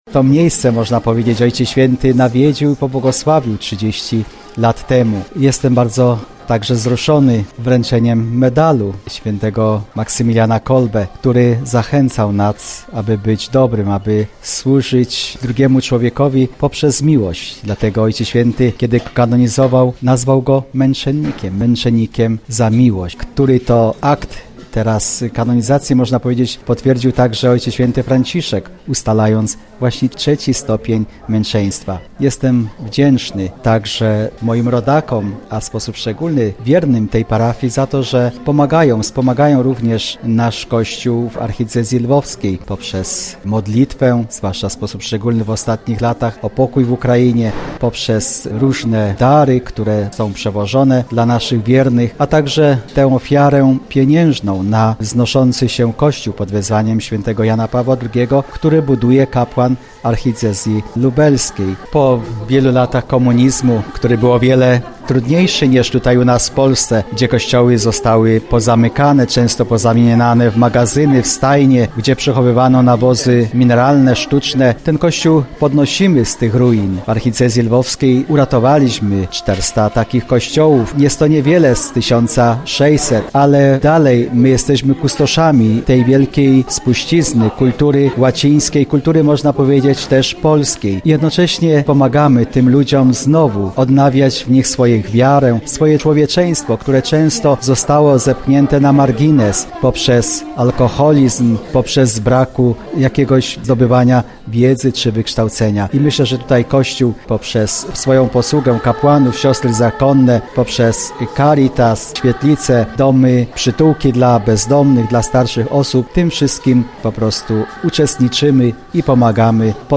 Rozmawiając z dziennikarzami już po zakończeniu uroczystości Abp Mieczysław Mokrzycki, który był sekretarzem II papieży – Jana Pawła II i Benedykta XVI – nawiązał do wizyty w Lublinie w czerwcu 1987 roku Papieża Polaka.